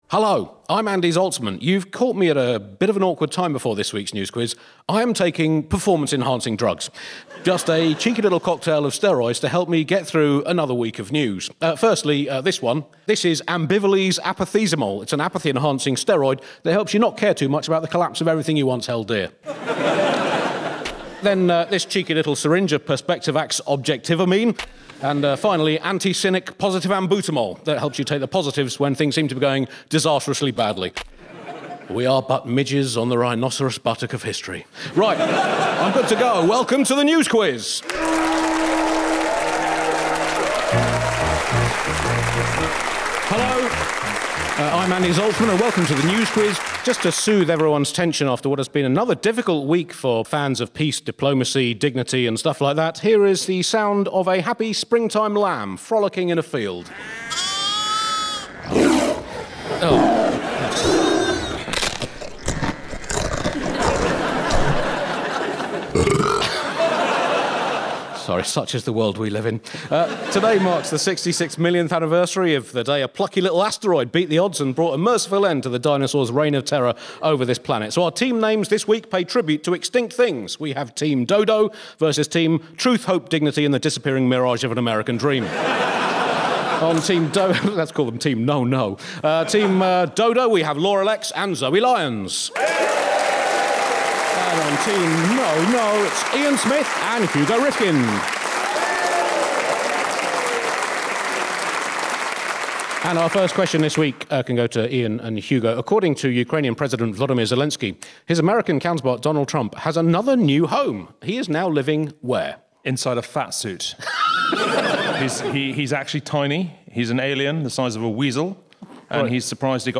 Topical panel quiz show, taking its questions from the week's news stories.